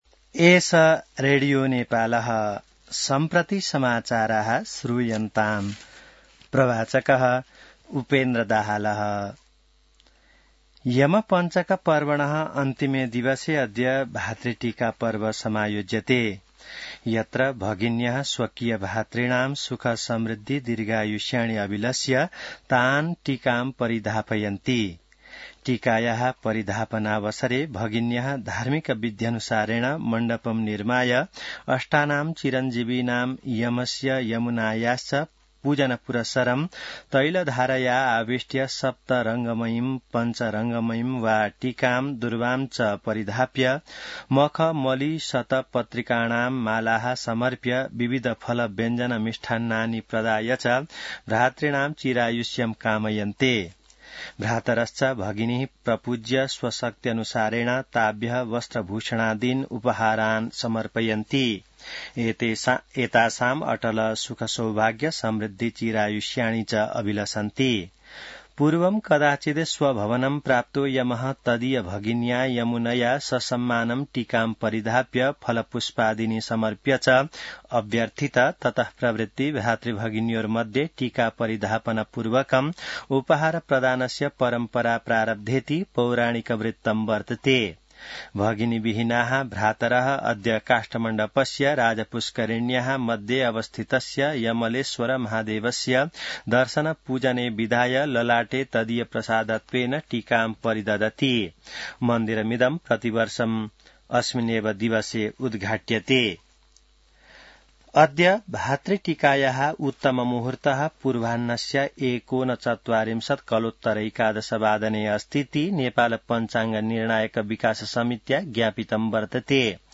संस्कृत समाचार : ६ कार्तिक , २०८२